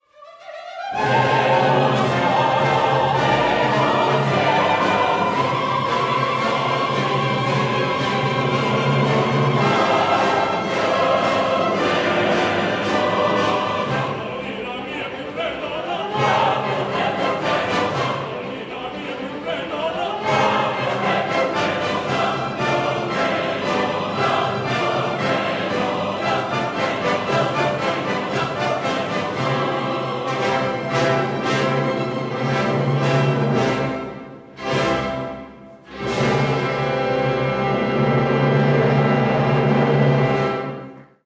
bass
tenor
mezzo-soprano
soprano
music assistant and fortepiano
melodramma in two acts